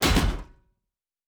Fantasy Interface Sounds
Weapon UI 12.wav